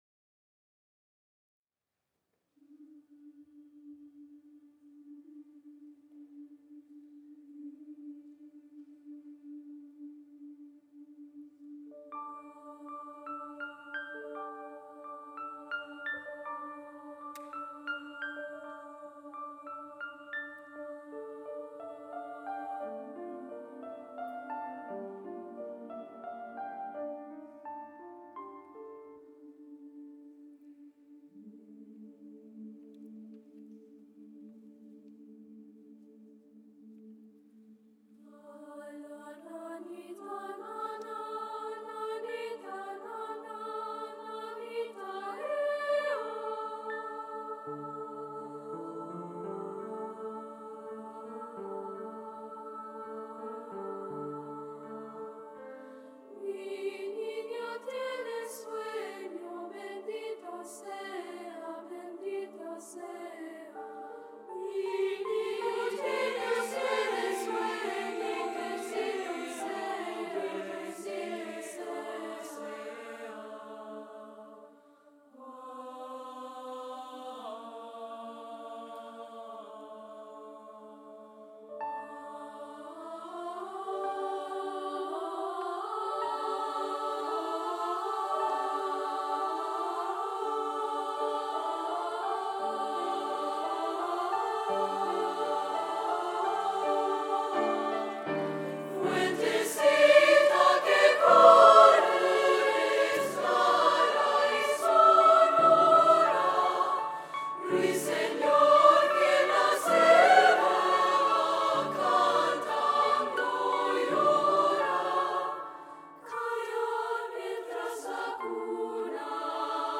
For SSA and piano (harp, or guitar)